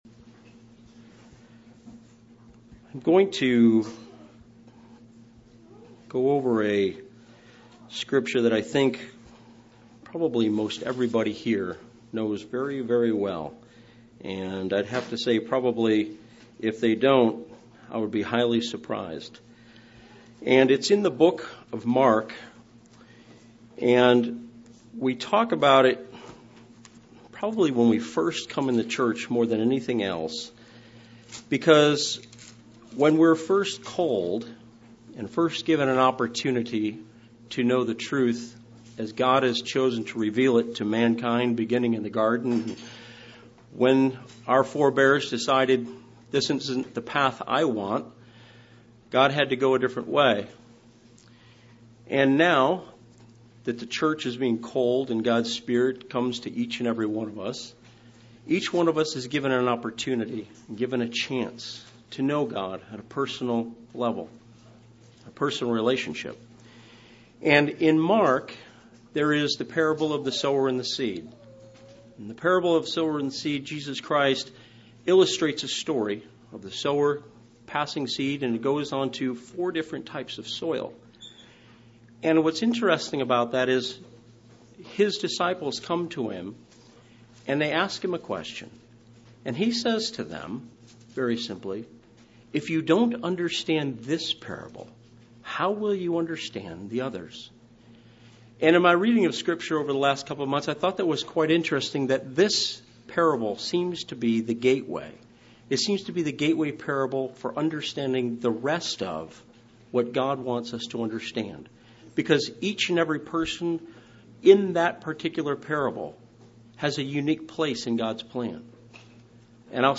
This split sermon goes over the parable of the sower.
Given in Lawton, OK